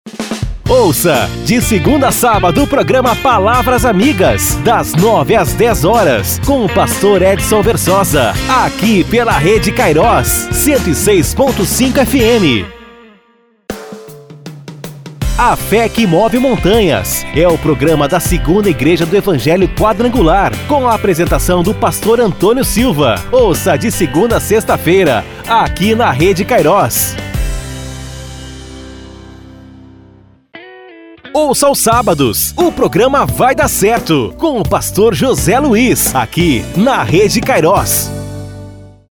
DEMO CHAMADAS PROGRAMAS GOSPEL: